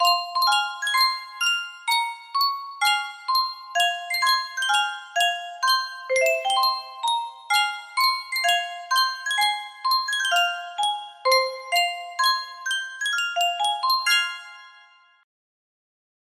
Yunsheng Music Box - Douki no Sakura 6715
Full range 60